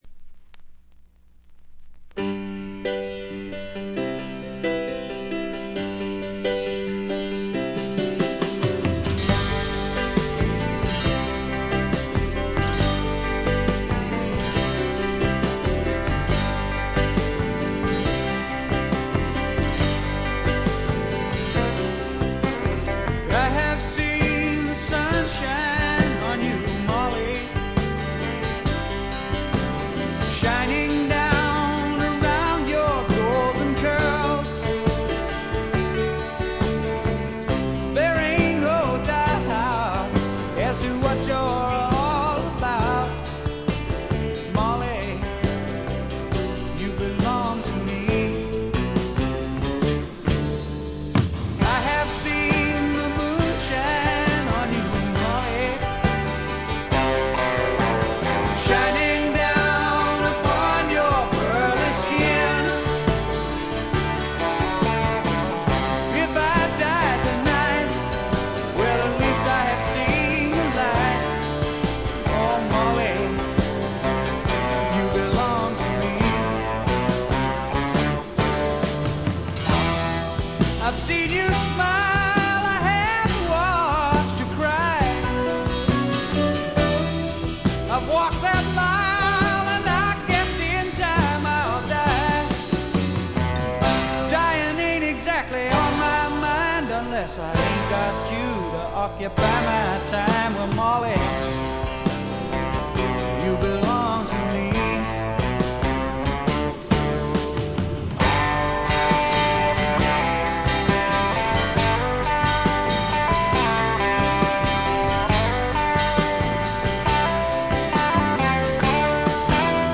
静音部でチリプチが聴かれますがおおむね良好に鑑賞できると思います。
試聴曲は現品からの取り込み音源です。